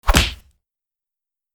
Tiếng Cú Đấm bịch (phim võ thuật)
Thể loại: Đánh nhau, vũ khí
tieng-cu-dam-bich-phim-vo-thuat-www_tiengdong_com.mp3